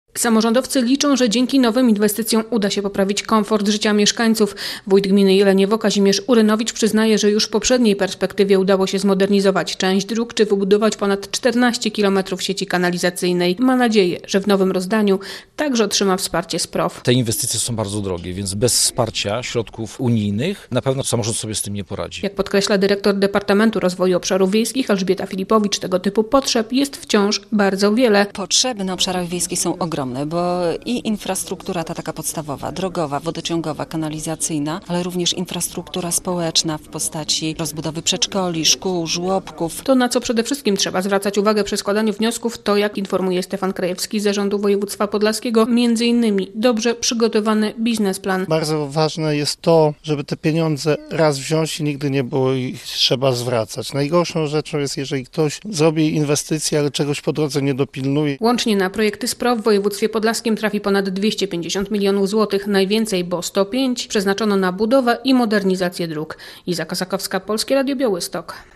Spotkanie urzędników i samorządowców w Szelmencie. Dyskusja o PROW 2014-2020 - relacja